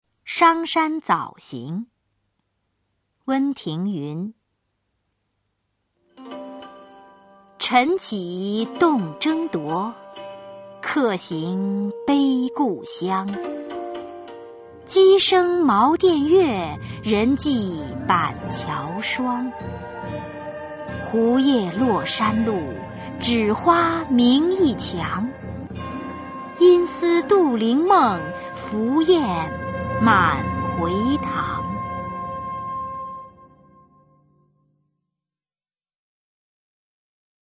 [隋唐诗词诵读]温庭筠-商山早行 配乐诗朗诵